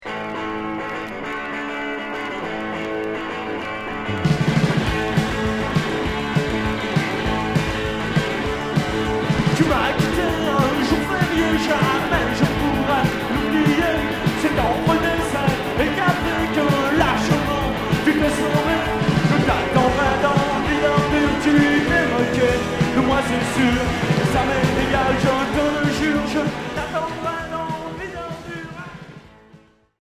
live
Rock